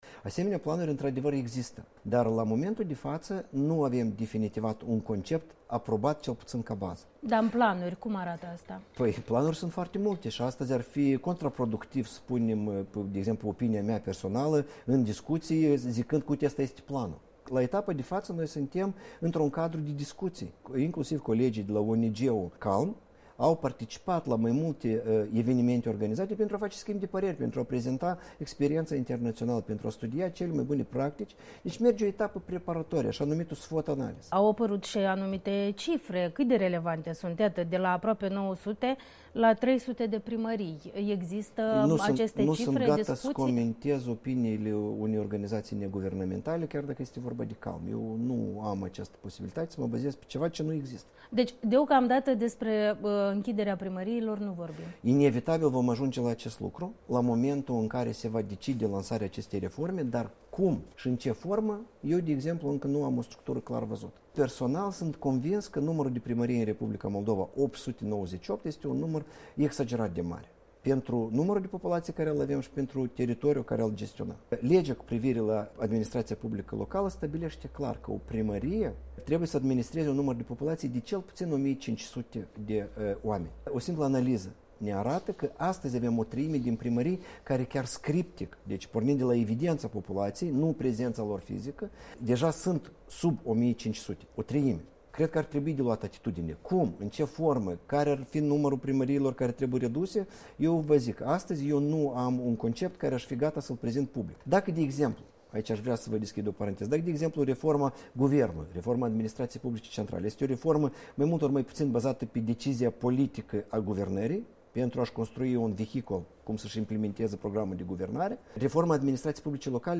Interviu cu Iurie Ciocan